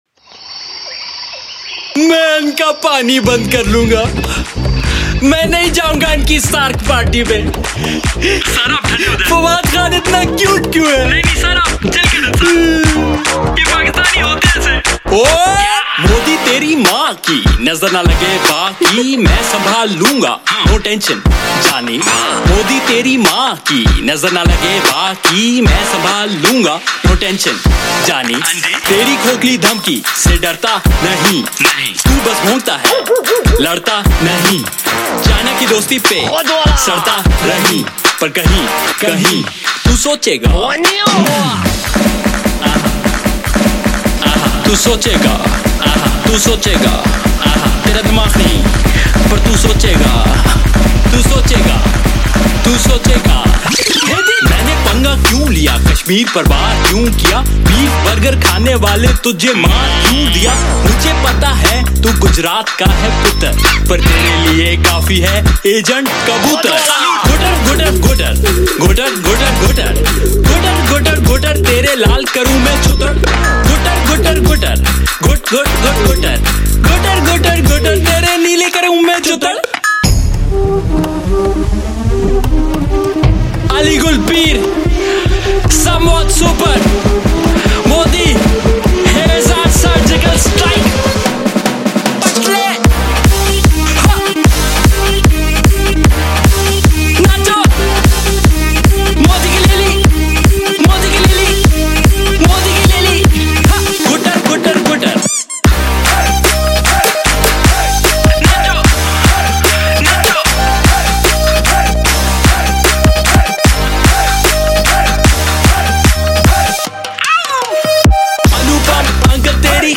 Pakistani Music